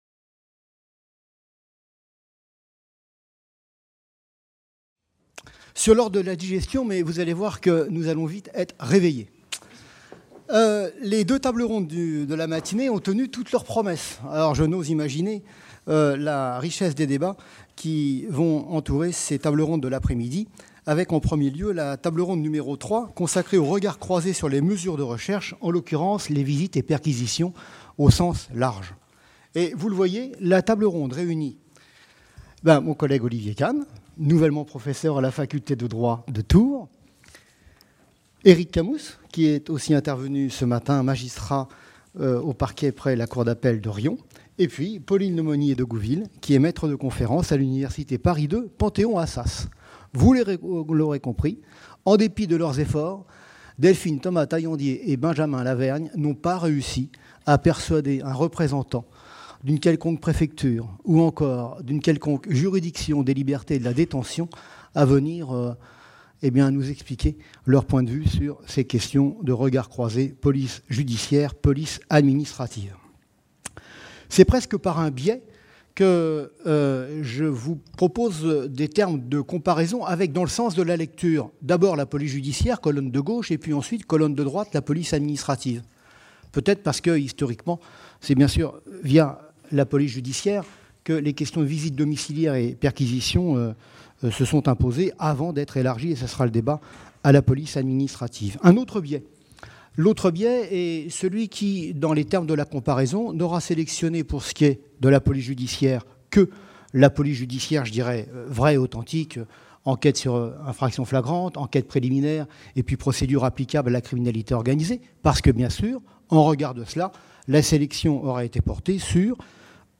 Table Ronde n°3 : Regards croisés sur les mesures de recherche (visites et perquisitions) | Canal U